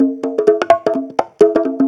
Conga Loop 128 BPM (19).wav